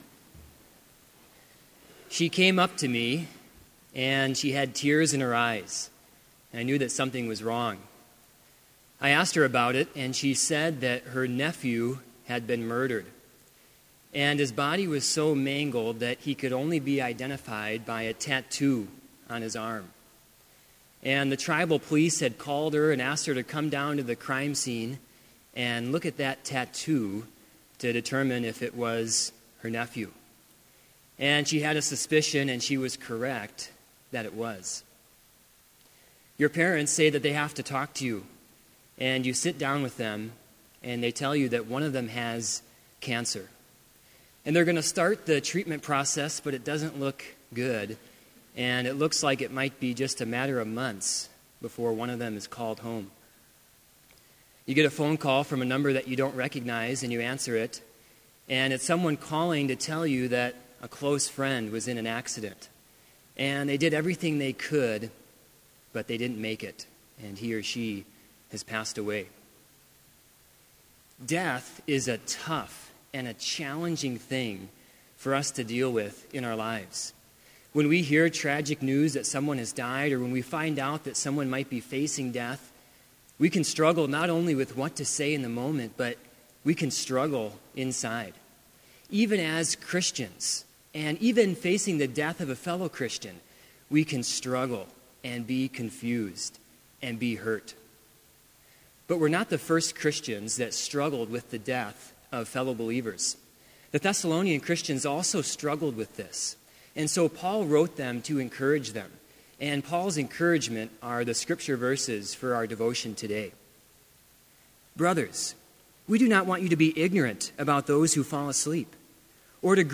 Complete Service
• Prelude
• Hymn 359, vv. 1-3, This Is the Feast
• Devotion
This Chapel Service was held in Trinity Chapel at Bethany Lutheran College on Wednesday, November 11, 2015, at 10 a.m. Page and hymn numbers are from the Evangelical Lutheran Hymnary.